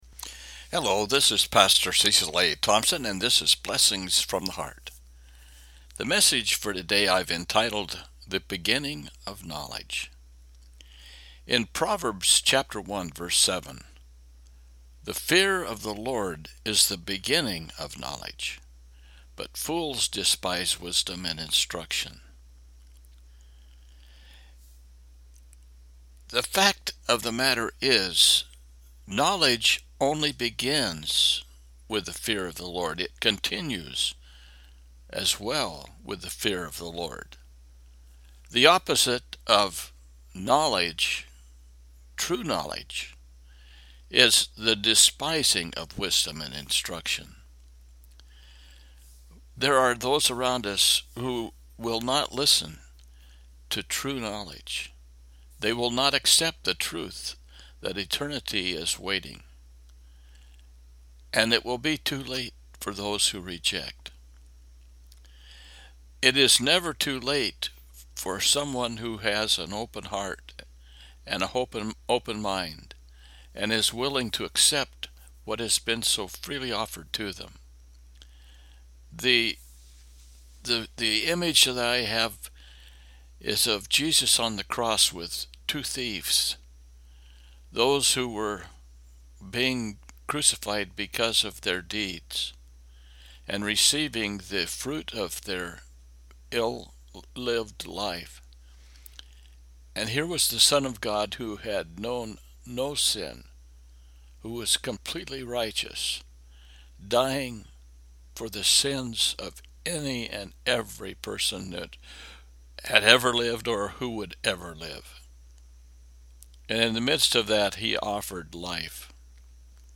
Proverbs 1:7 – Devotional